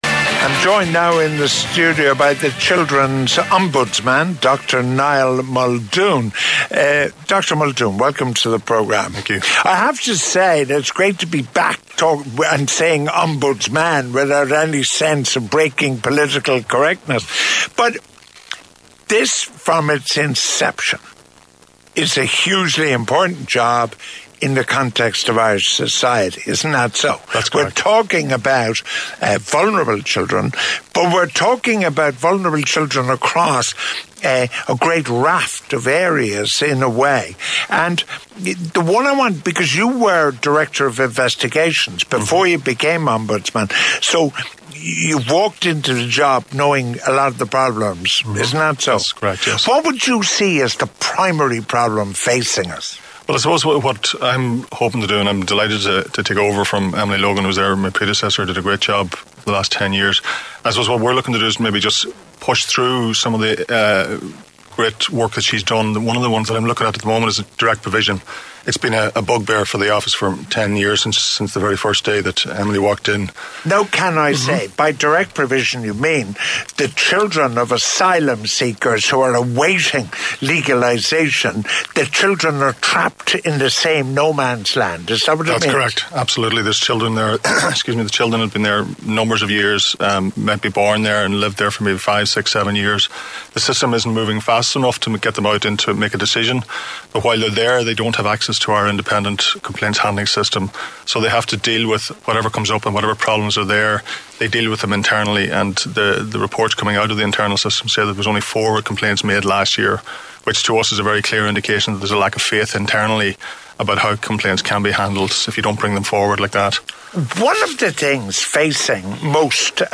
NEWSTALK-INTERVIEW.mp3